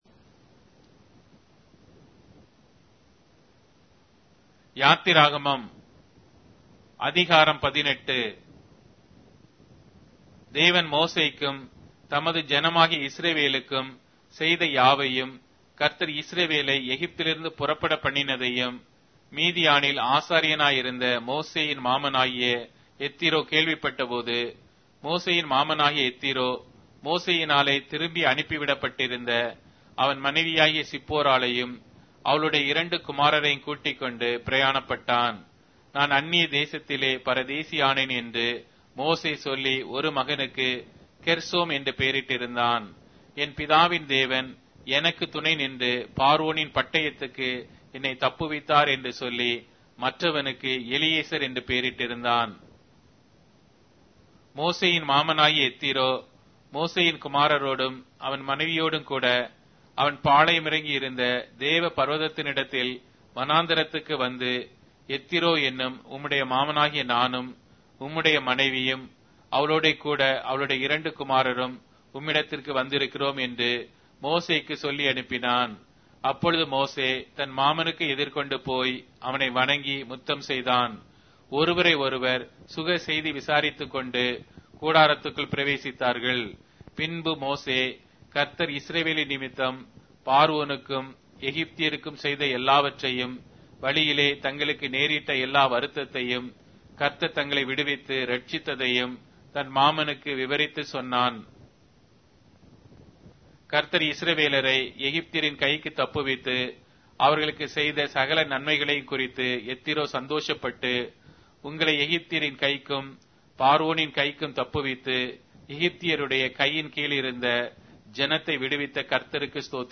Tamil Audio Bible - Exodus 27 in Ervhi bible version